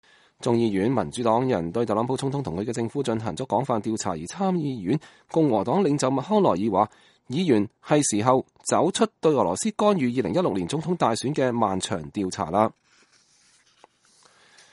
參議院多數黨領袖麥康奈爾在國會對記者講話2019年4月2日。